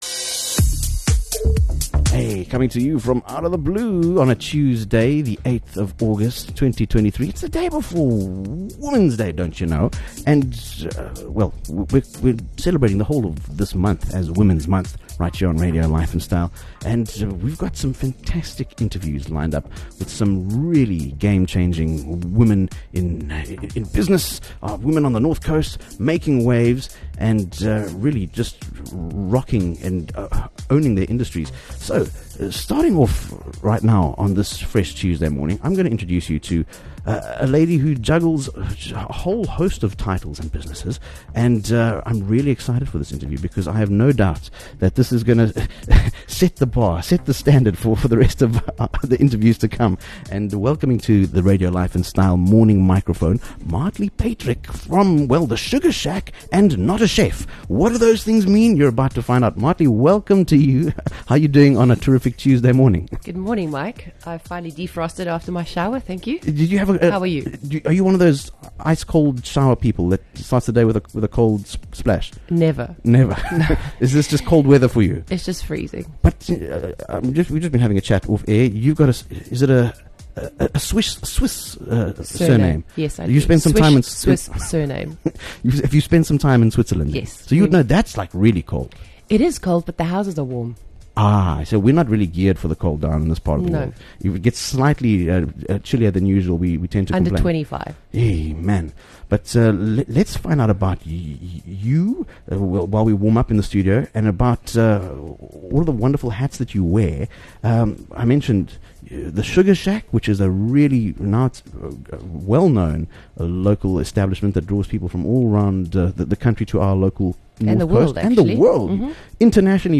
A special Women's Day Interview